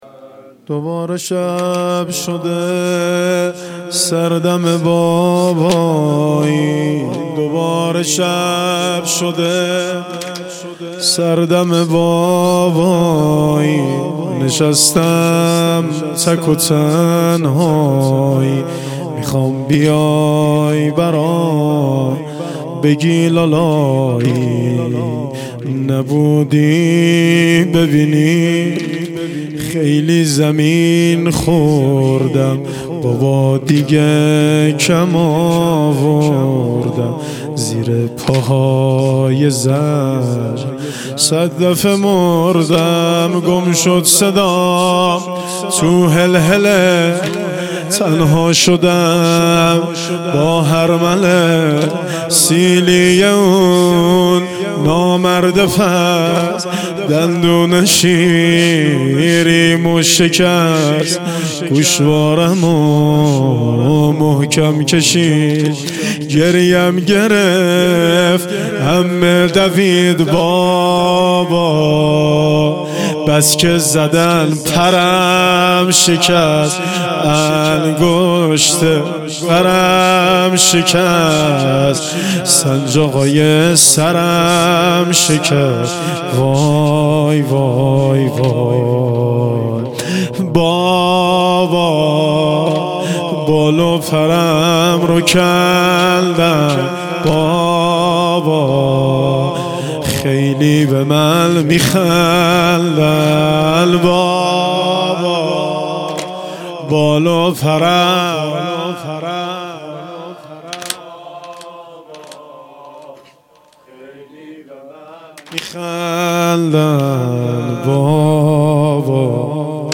واحد شب سوم محرم 1400
شب سوم محرم 1400